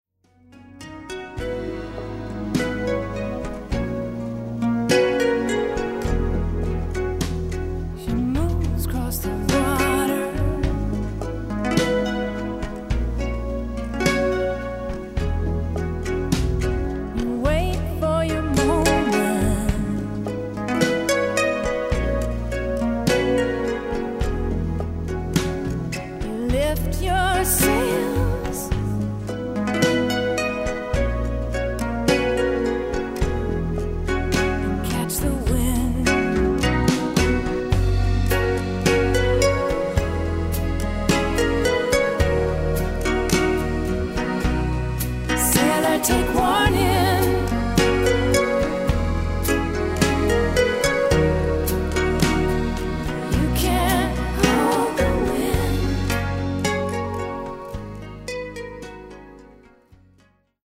folk harp
Recorded & mixed at Powerplay Studios, Maur – Switzerland